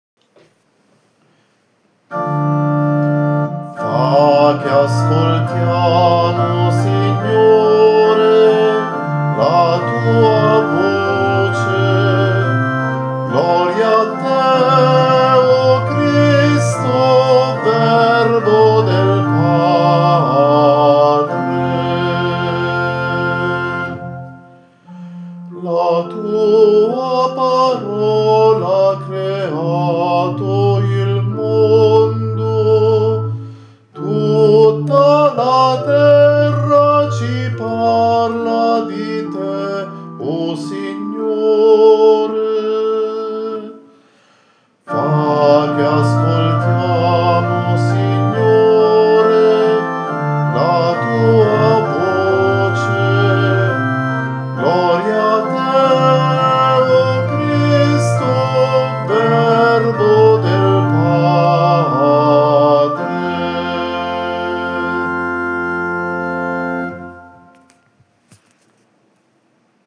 un canto di lode alla Parola
per Organo e Coro a 4 voci miste